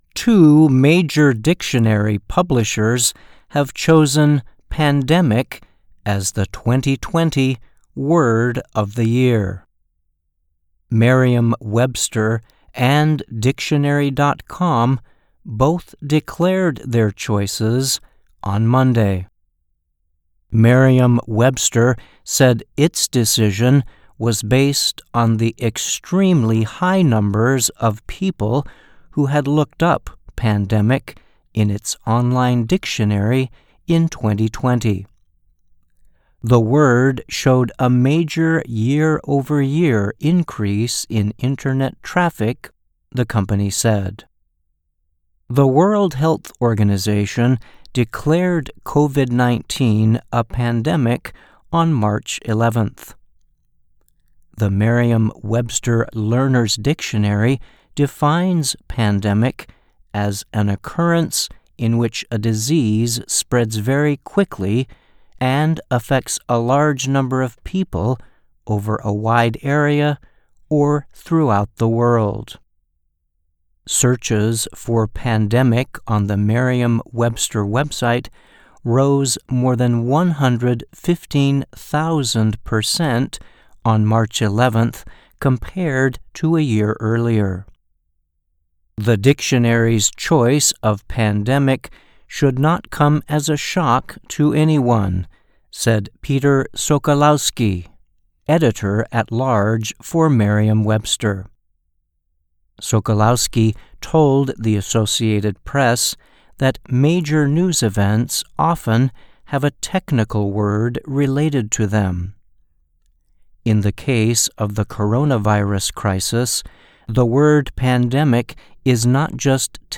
Kalau bisa diusahakan bacanya mirip dengan native speaker dibawah ini, oke..